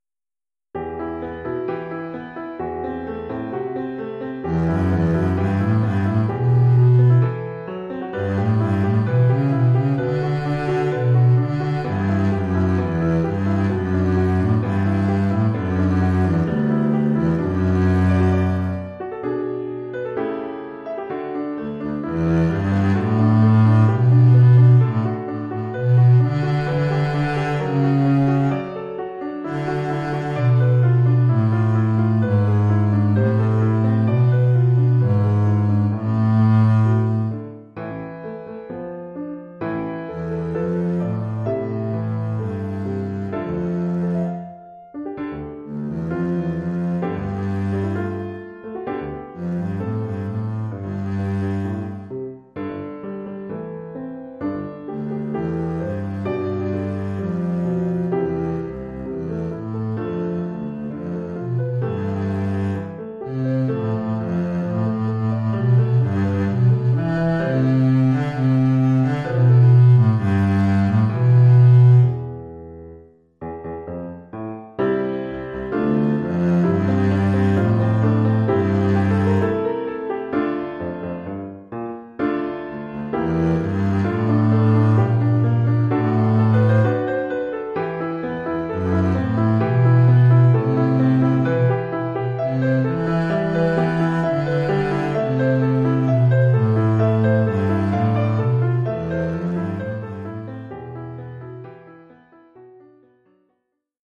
Oeuvre pour contrebasse et piano.